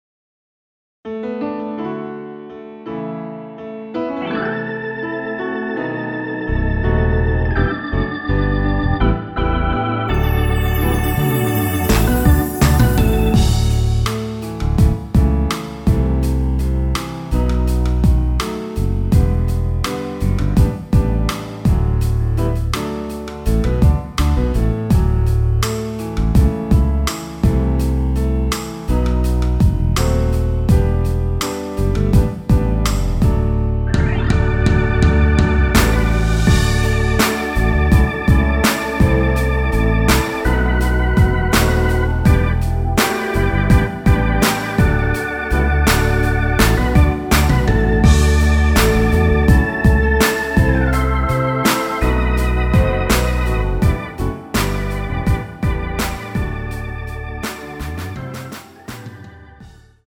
◈ 곡명 옆 (-1)은 반음 내림, (+1)은 반음 올림 입니다.
앞부분30초, 뒷부분30초씩 편집해서 올려 드리고 있습니다.
중간에 음이 끈어지고 다시 나오는 이유는
국내곡